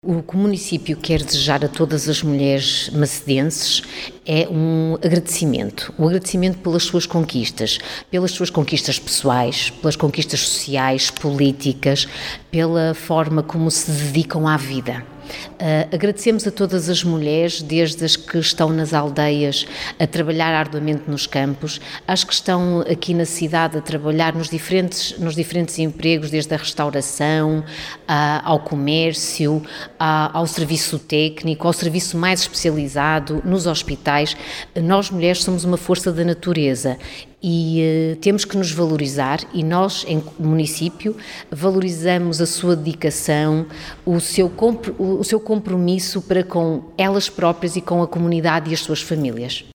Presente esteve também a vereadora da autarquia de Macedo, Susana Viana, que deixou uma mensagem a todas as mulheres macedenses: